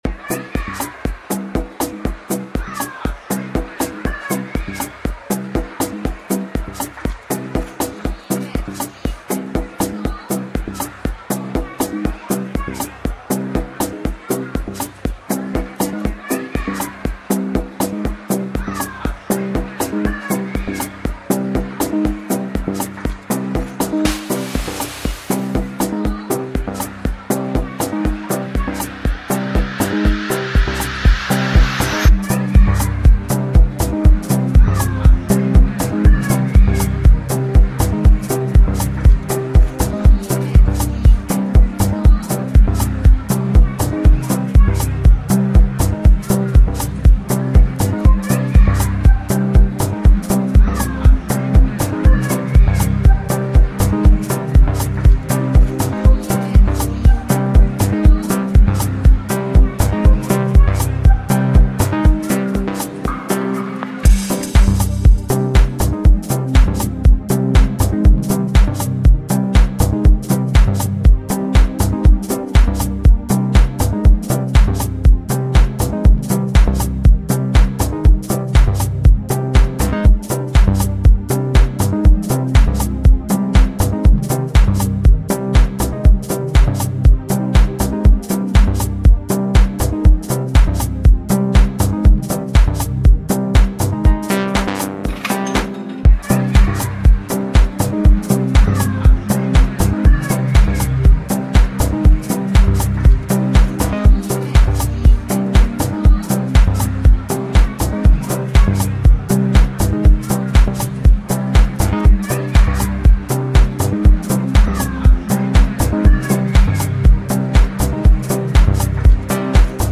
deep dubby but so danceable.
The second is the dub version of the original.
A great package for the lovers of the deep tech sound.